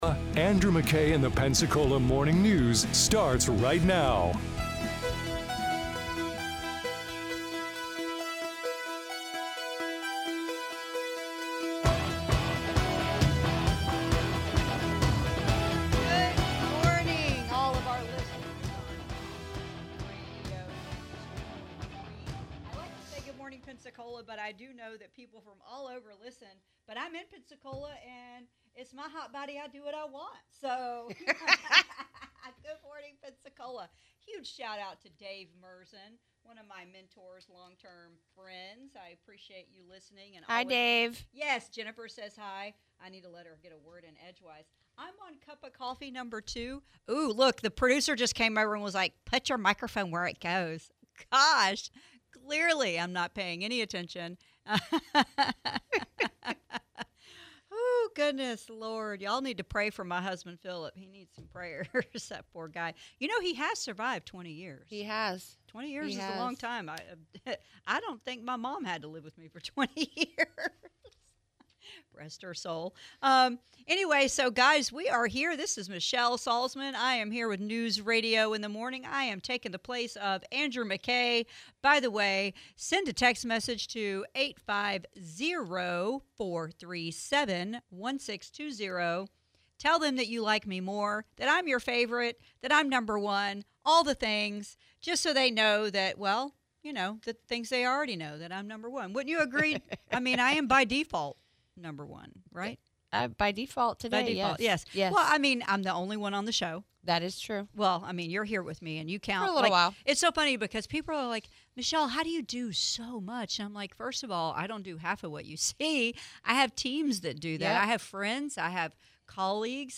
Michelle Salzman discusses helping constituents navigate government, medical freedom issues in FL, interviews State GOP Chair Evan Power.